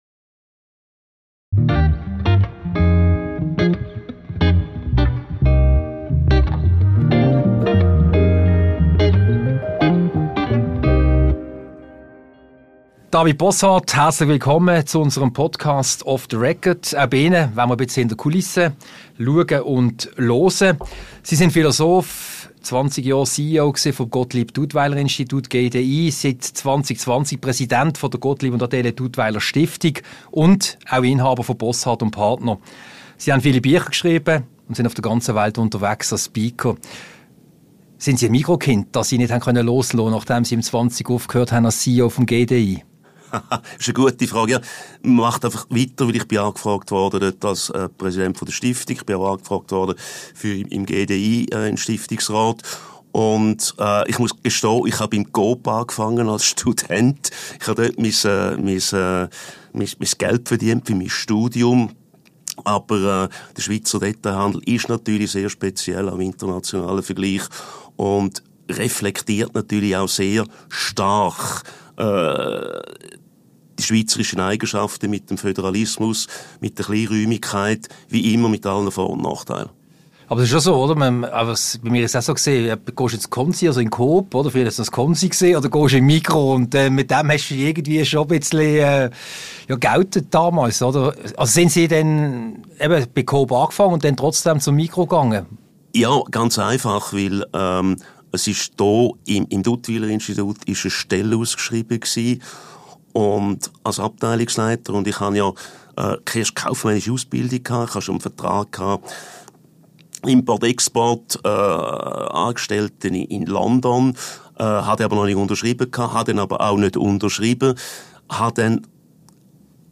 Ein Gespräch über die Erfahrungen als CEO, den Blick des Philosophen auf den Zustand der Welt und was dies für KMU bedeutet.